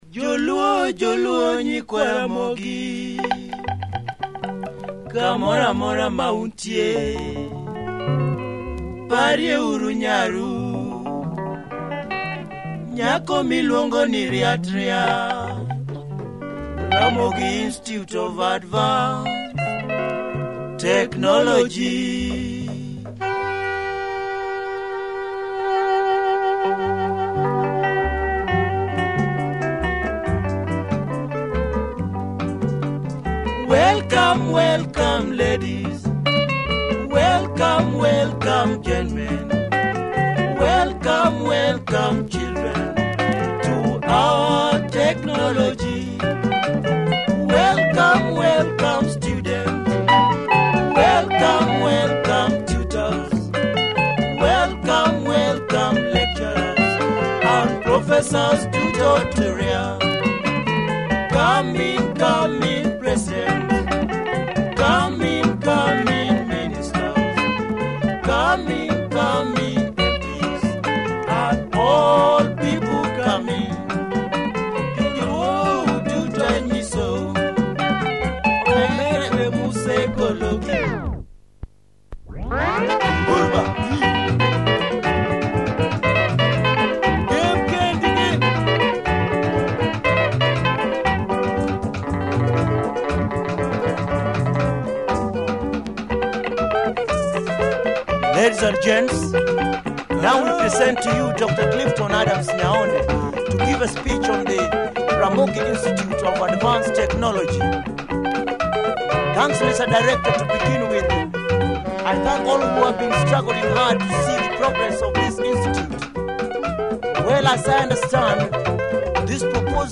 Nice luo rumba with nice English vocals.
Steps up in the breakdown.